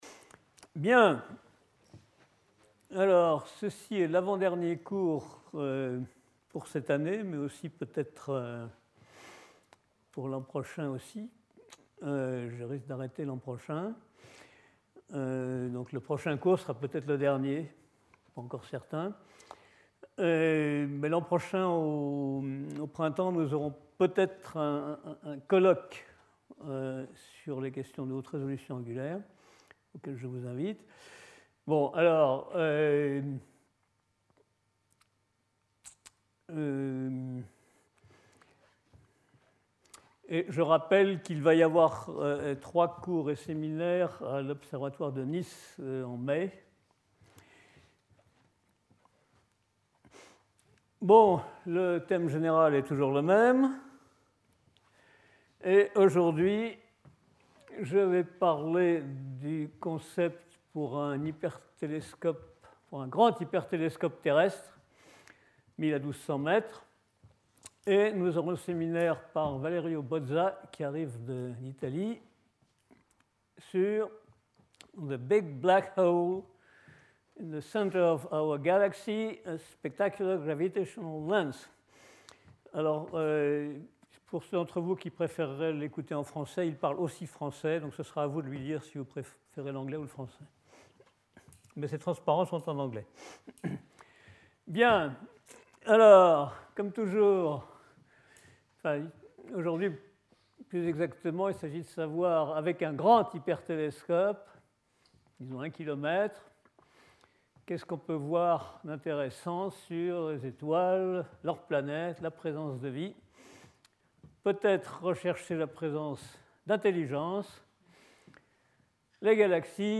Antoine Labeyrie Professor at the Collège de France
Lecture